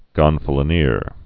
(gŏnfə-lə-nîr)